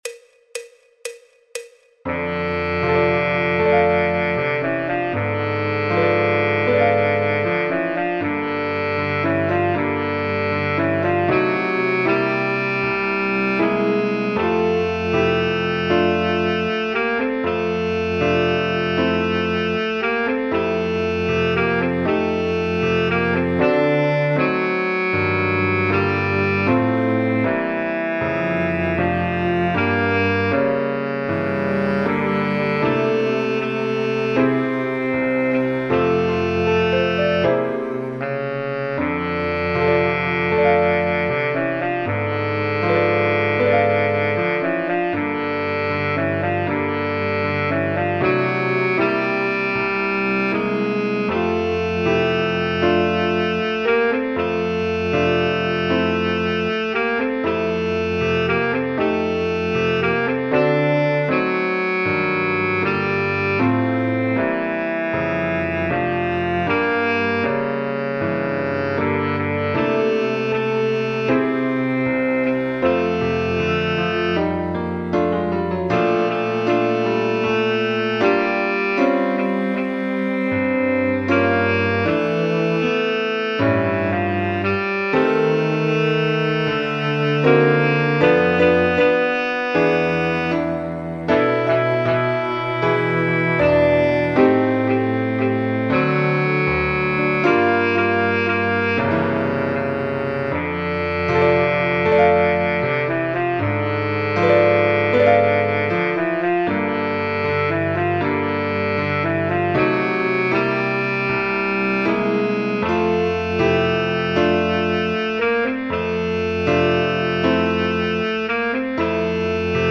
El MIDI tiene la base instrumental de acompañamiento.
Saxofón Tenor / Soprano Sax
Sol Mayor
Jazz, Popular/Tradicional